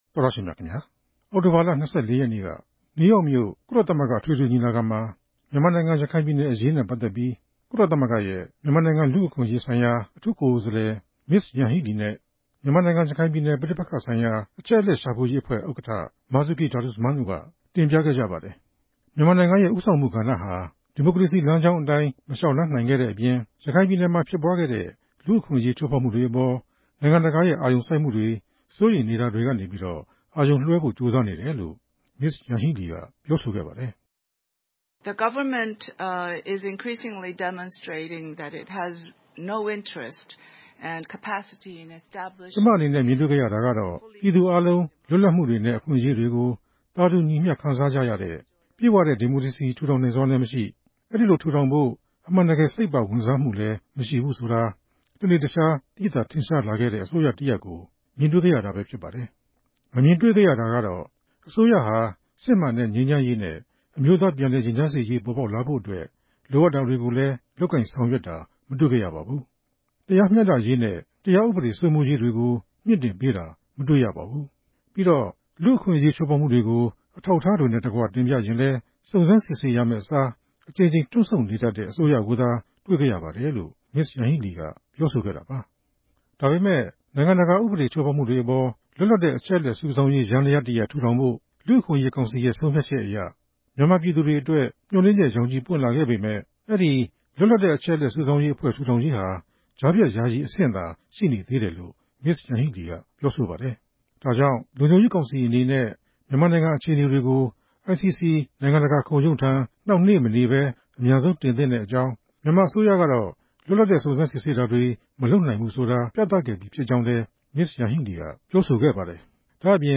မြန်မာ့အရေး ကုလသမဂ္ဂ သတင်းစာရှင်းလင်းပွဲ